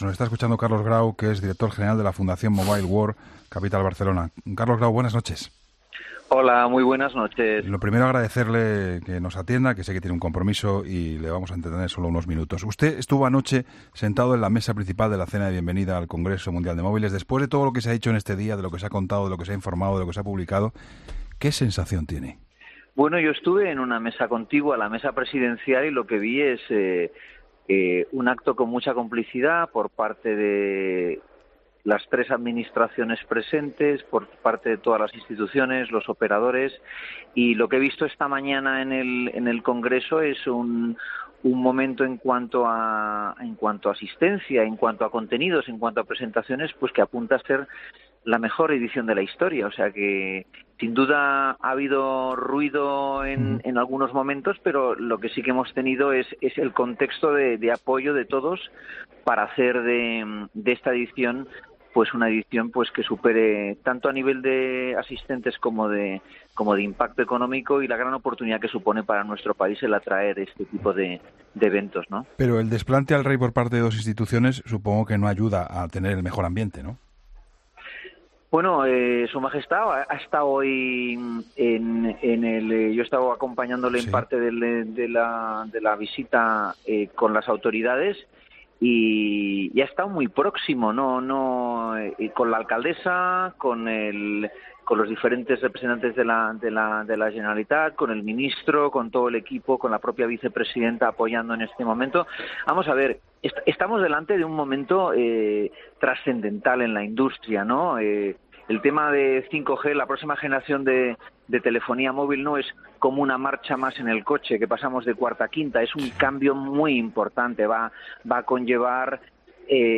Entrevistas en La Linterna